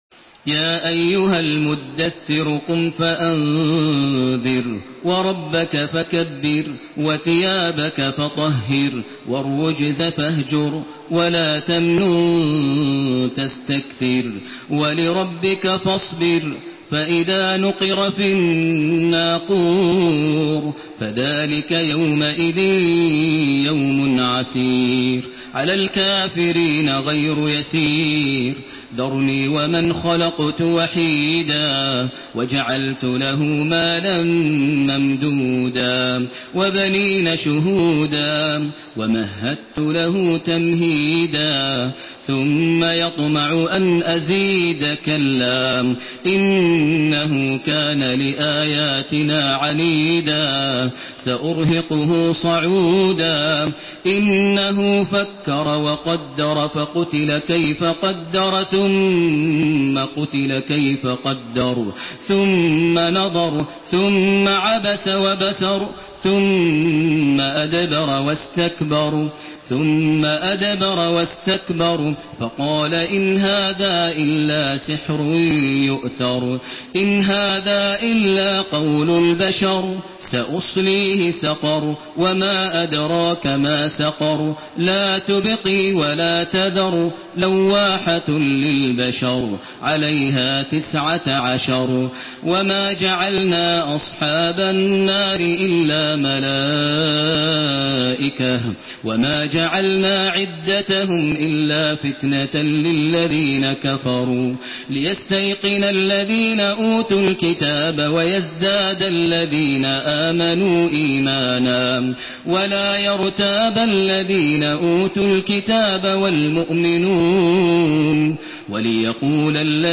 Surah আল-মুদ্দাস্‌সির MP3 in the Voice of Makkah Taraweeh 1432 in Hafs Narration
Murattal